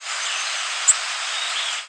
Short rising seeps
Golden-cheeked Warbler